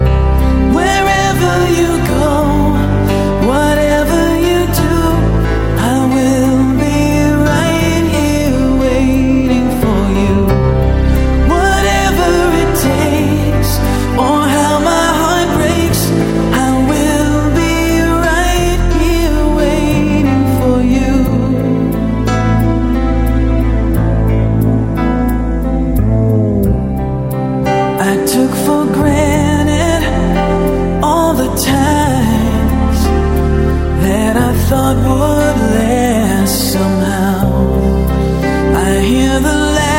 поп
баллады , романтические